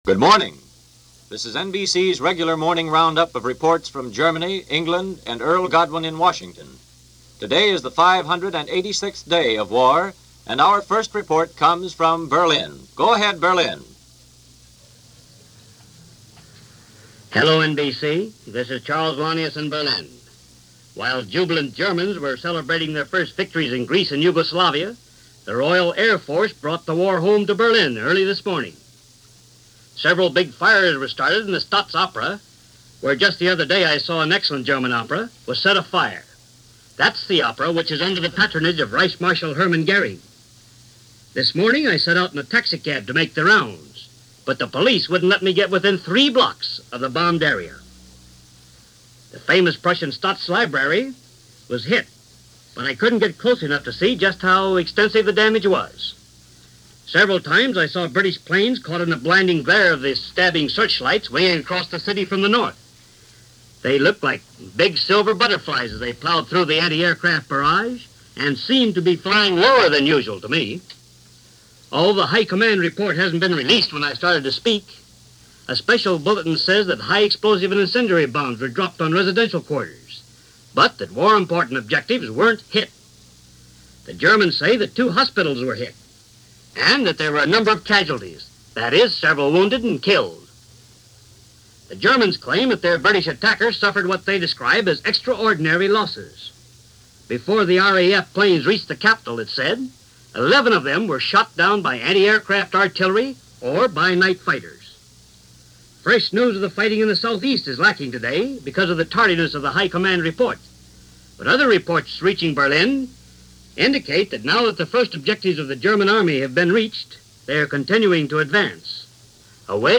RAF Brings War To Berlin - German Army Reaches Salonika - War News Worries Capitol Hill - April 10, 1941 - NBC Radio